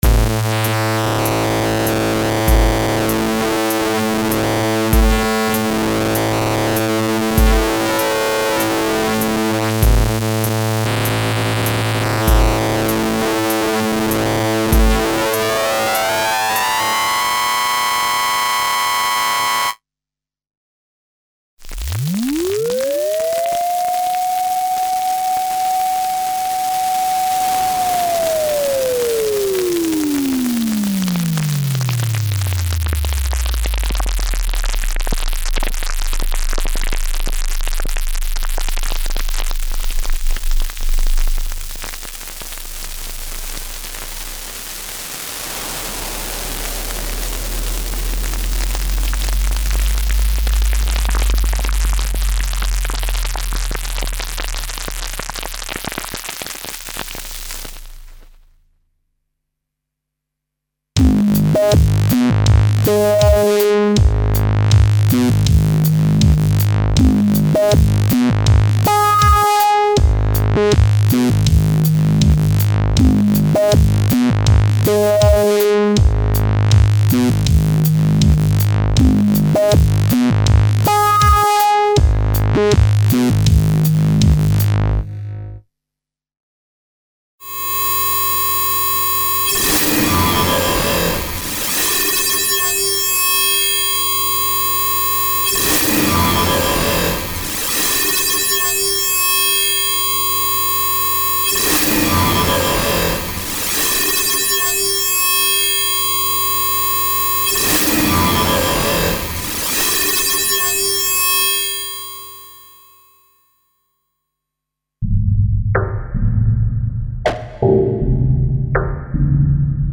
Oscillator sync and synth effect collection - razor sharp, absolute sonic extreme with no limit, created to take advantage of the special controller settings for internal DSP modulations (e.g. filter, pitch, shaper, wrap, distortion, etc.).
Info: All original K:Works sound programs use internal Kurzweil K2600 ROM samples exclusively, there are no external samples used.